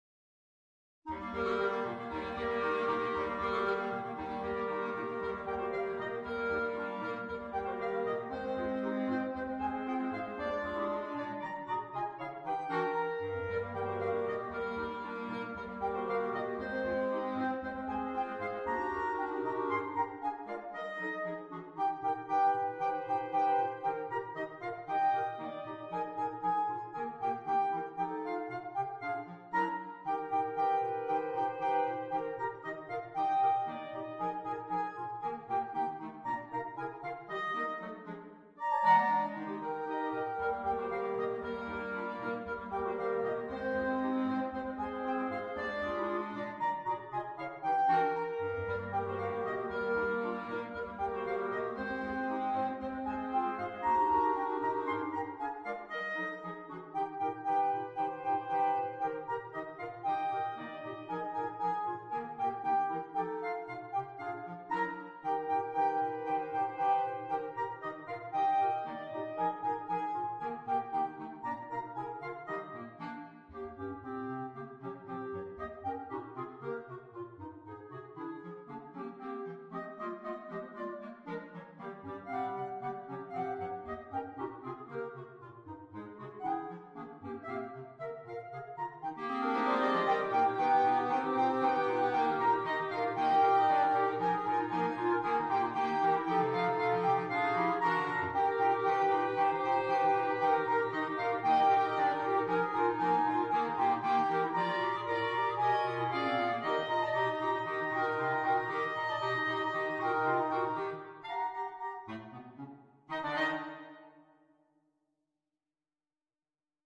per 4 clarinetti e clarinetto basso